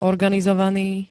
organizovaný [-n-] -ná -né 2. st. -nejší príd.
Zvukové nahrávky niektorých slov